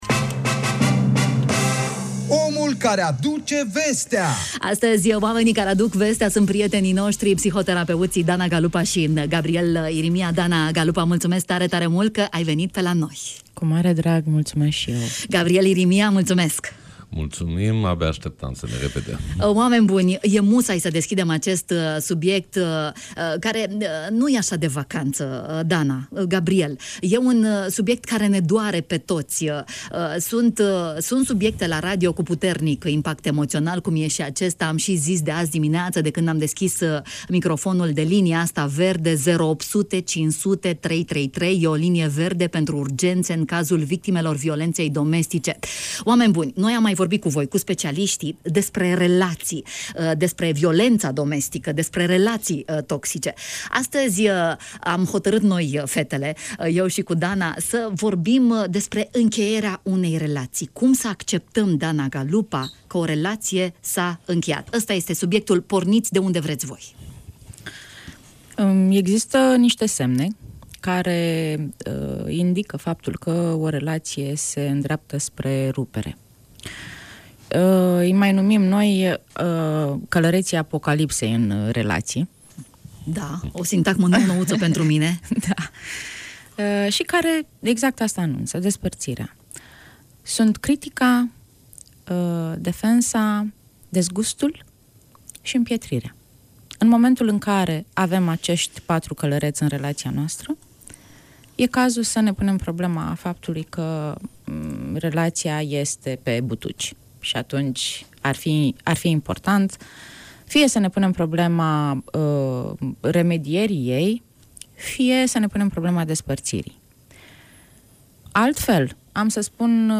psihoterapeuți